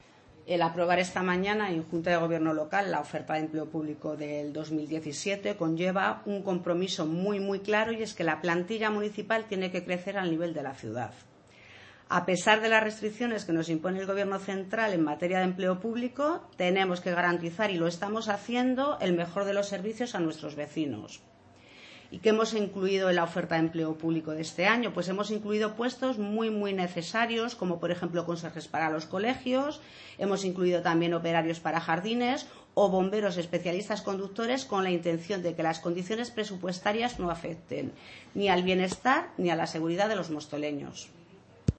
Audio - Susana García (Concejal de Régimen Interior) Sobre Oferta Empleo Público